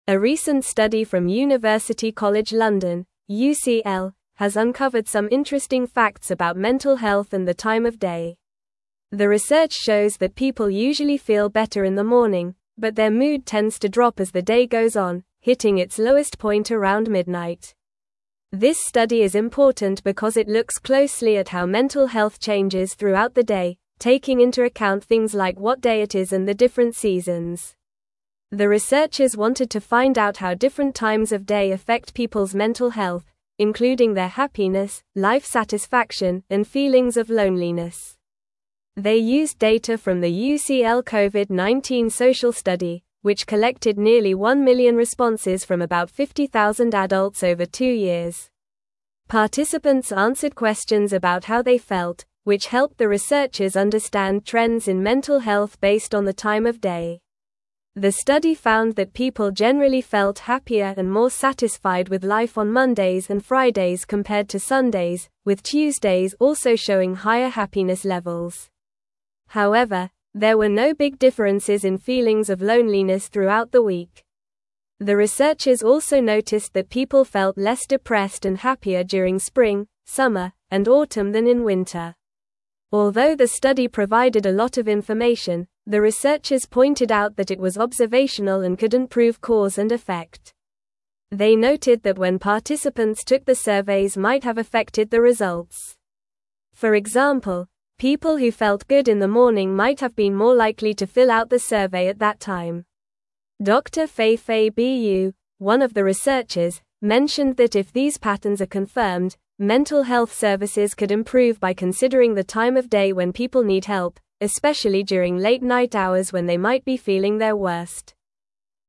Normal
English-Newsroom-Upper-Intermediate-NORMAL-Reading-Mental-Health-Declines-Throughout-the-Day-Study-Finds.mp3